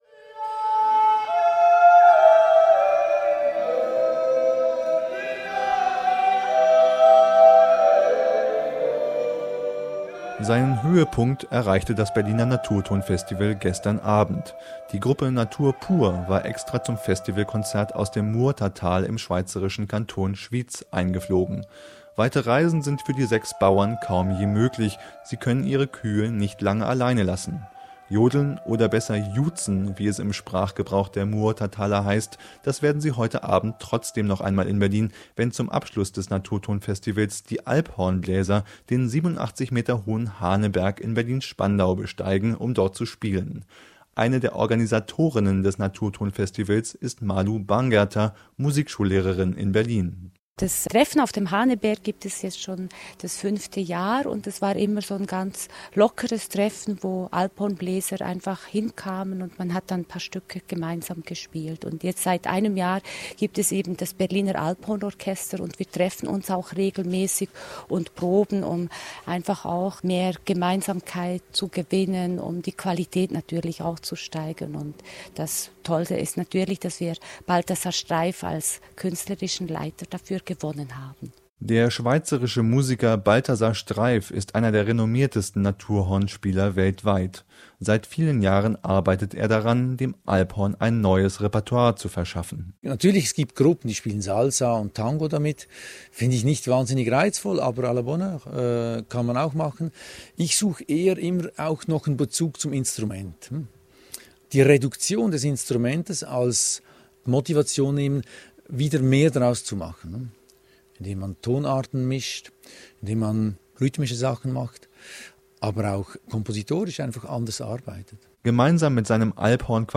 Zum Naturton-Festival 2012: